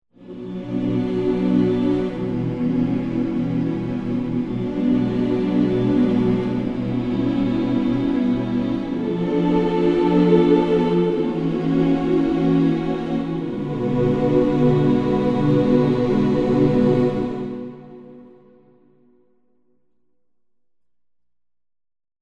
Gospel Hmms demo =1-D02.mp3